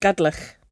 [gud-luch]